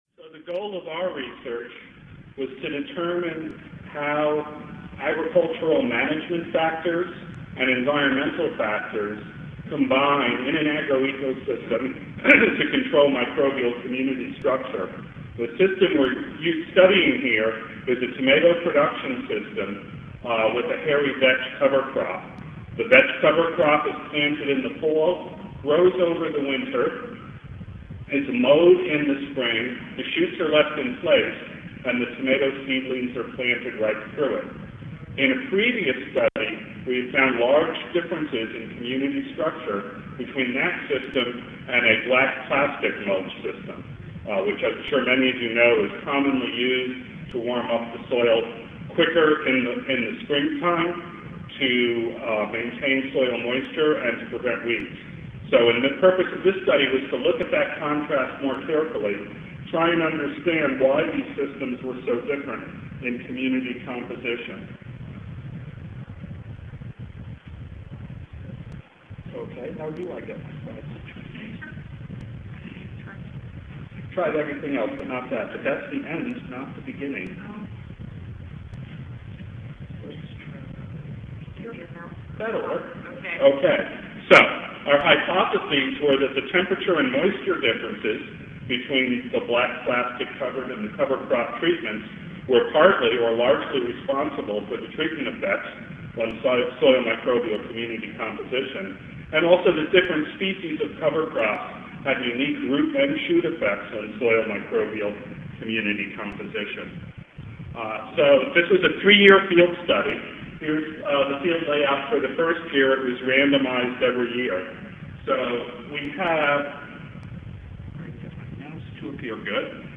Recorded presentation A replicated agroecological experiment was run in the field for three years in order to identify the major factors controlling soil microbial biomass and community structure.